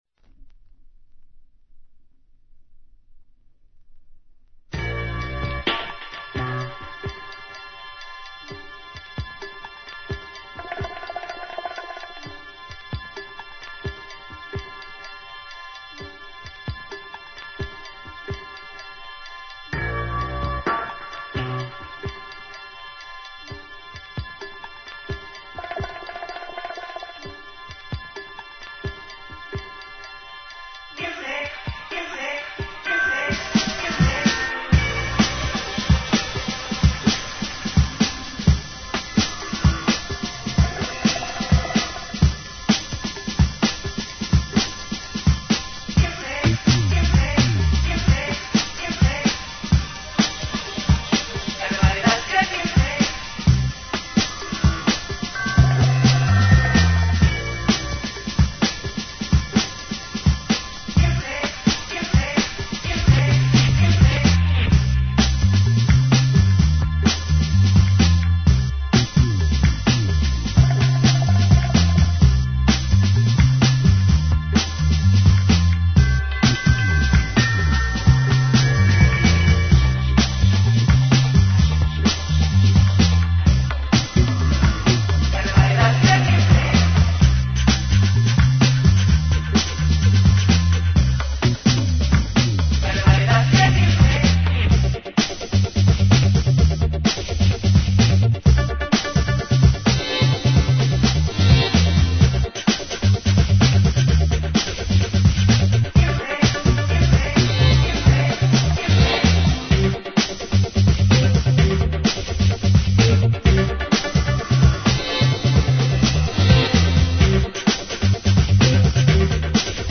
Breakbeats.